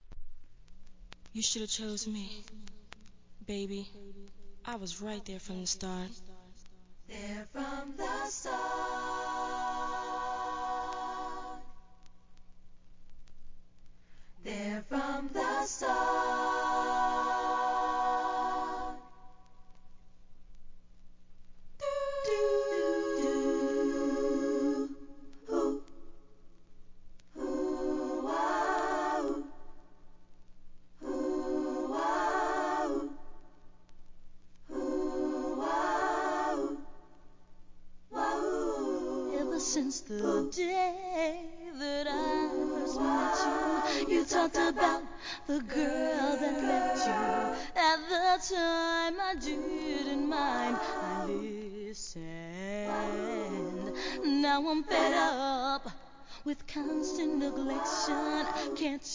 HIP HOP/R&B
アカペラで聴かせます♪